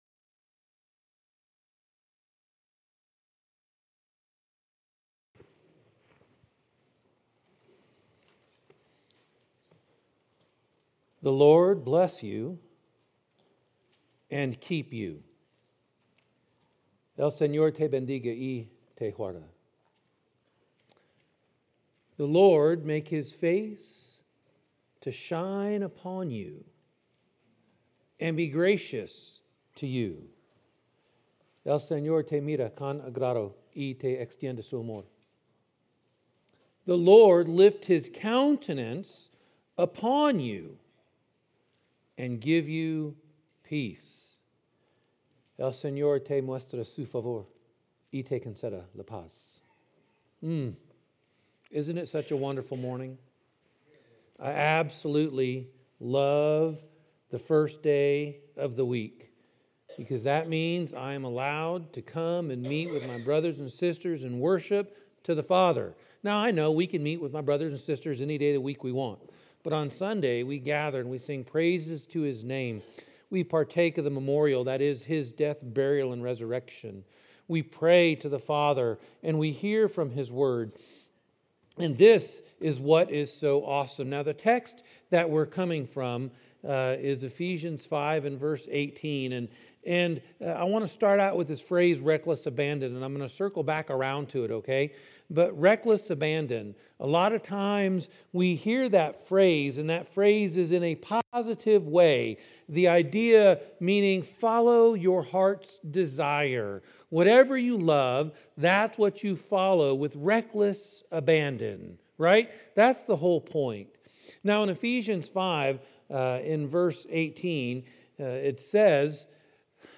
Mar 17, 2019 | by Sample List | series: Sample Sermon